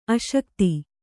♪ aśakti